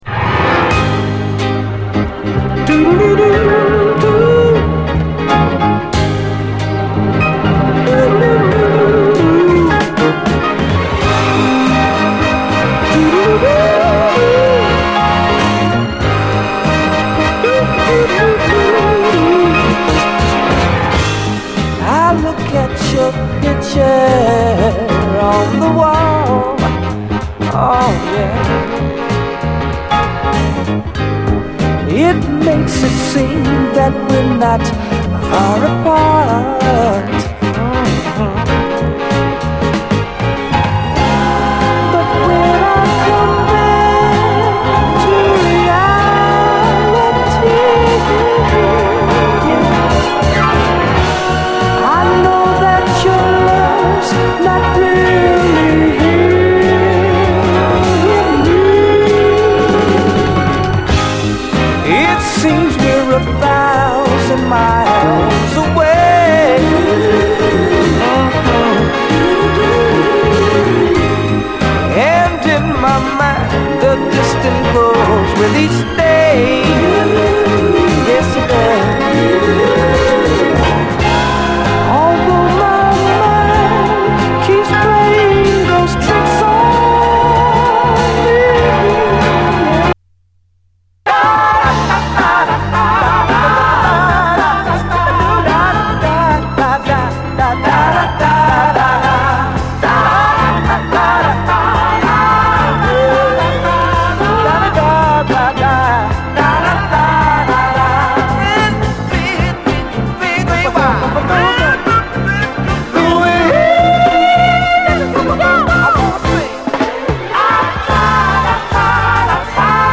SOUL, 70's～ SOUL, DISCO